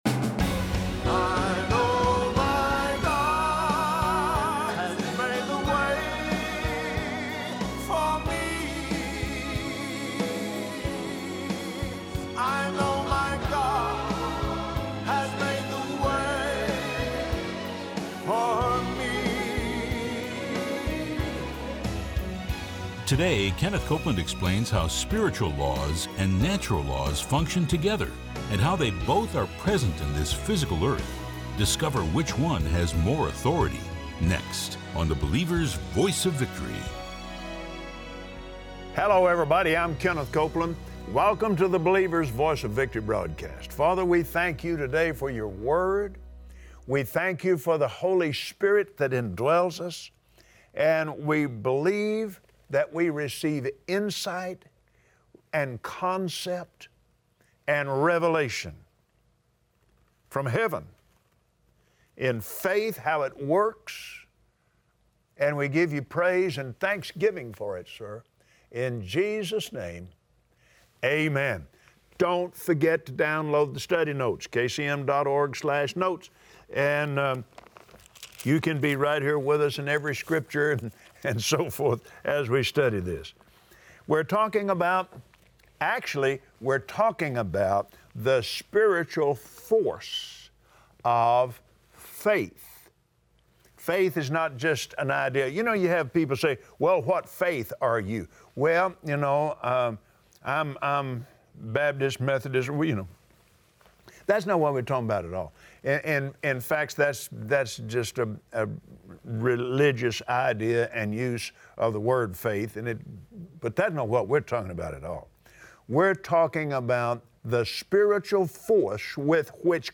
Believers Voice of Victory Audio Broadcast for Wednesday 08/02/2017 Watch Kenneth Copeland on Believer’s Voice of Victory explain how faith works by spiritual law. Unforgiveness breaks spiritual law and blocks the force of faith.